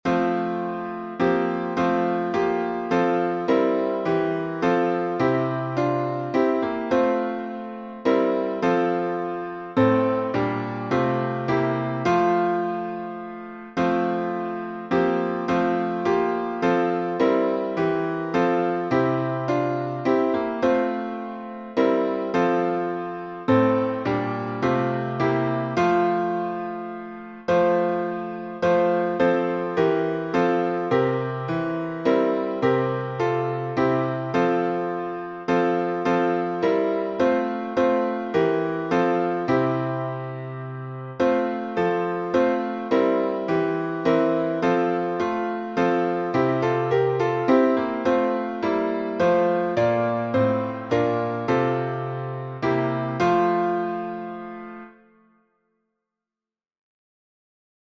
Our Great Savior — F major.
Key signature: F major (1 flat) Time signature: 3/4 Meter: 8.7.8.7. with Refrain